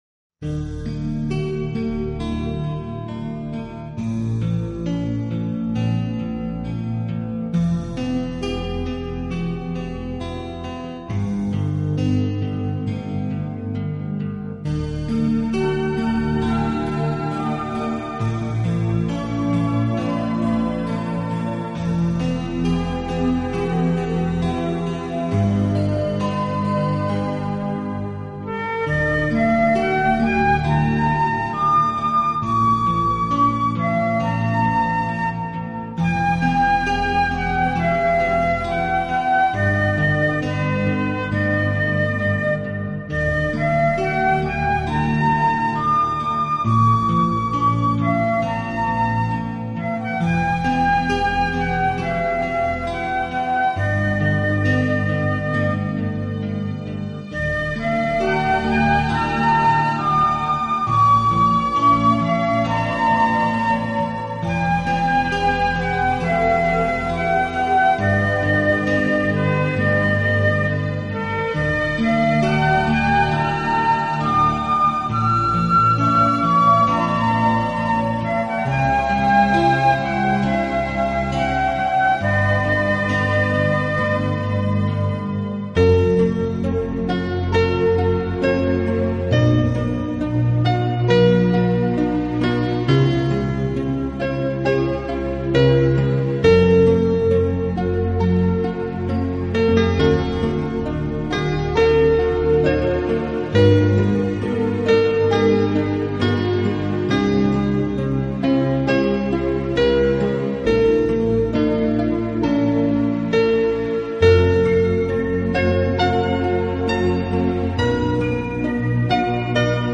纯音乐
听过他们音乐的读者大致可以了解，该团的音乐风格通常是以电子
合成乐音为演奏的主体，再结合他们所采撷的大自然音效，在这两相结合之下，您可
器配置，使每首曲子都呈现出清新的自然气息。